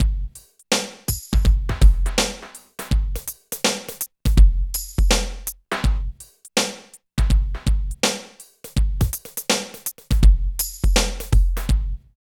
118 LOOP  -L.wav